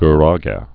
Gu·ra·ge
(g-rägĕ)